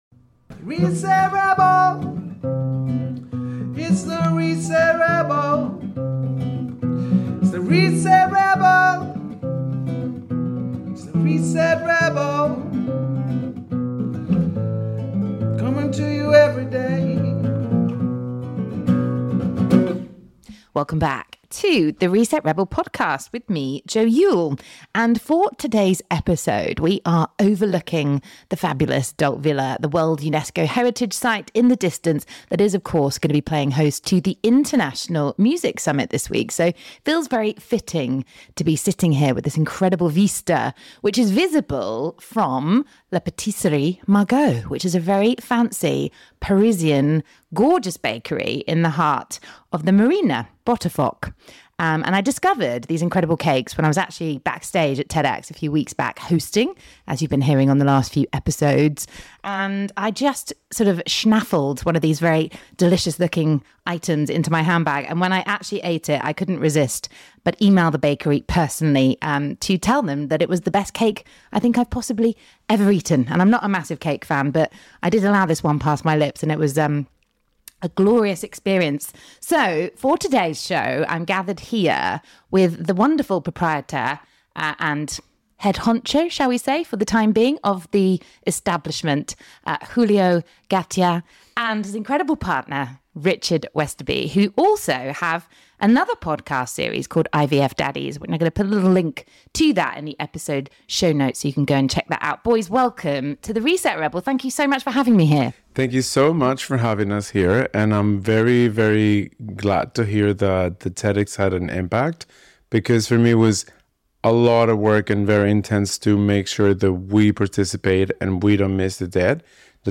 The pair are not just gorgeous, but funny and we had lots of fun meeting them at the Parisian style venue in the heart of Marina Botafoch.